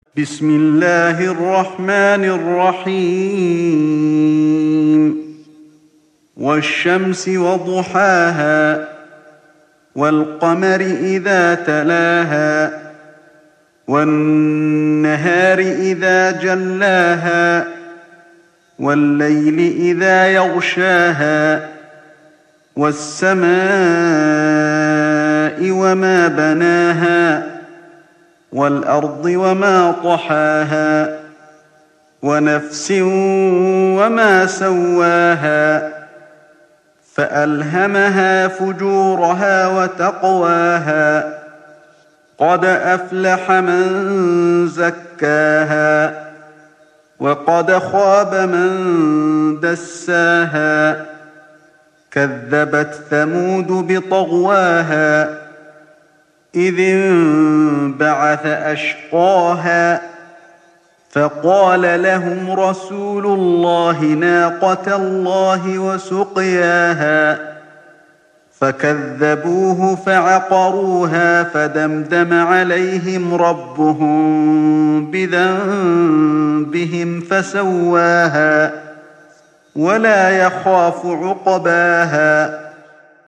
تحميل سورة الشمس mp3 بصوت علي الحذيفي برواية حفص عن عاصم, تحميل استماع القرآن الكريم على الجوال mp3 كاملا بروابط مباشرة وسريعة